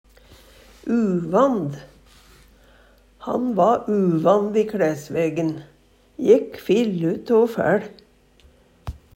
uvand - Numedalsmål (en-US)